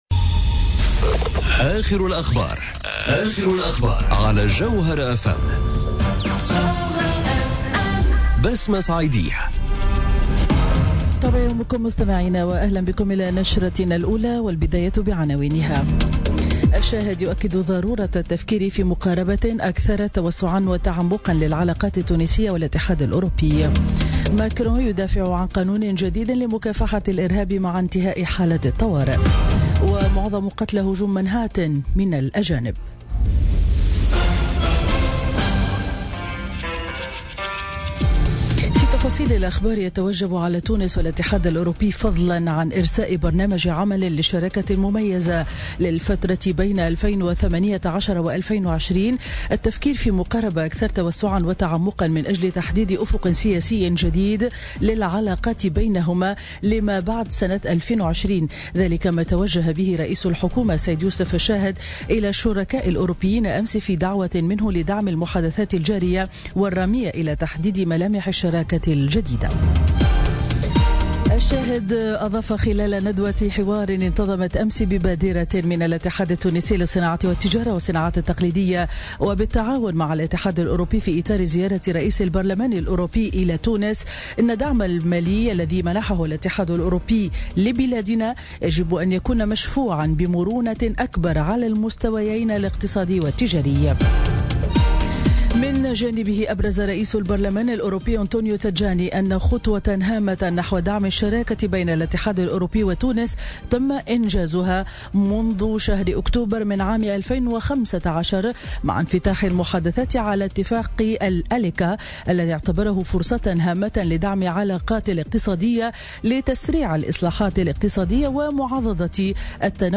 نشرة أخبار السابعة صباحا ليوم الإربعاء 1 نوفمبر 2017